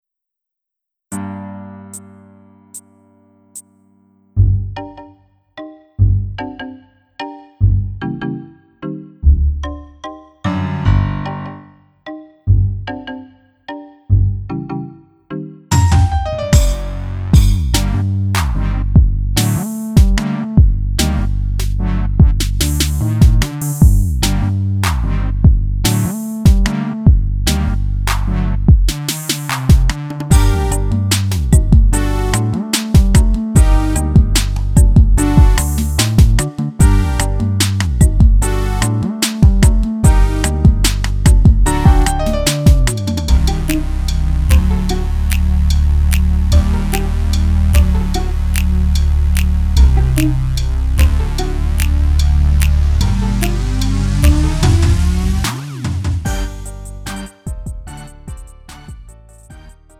음정 -1키 3:26
장르 가요 구분 Lite MR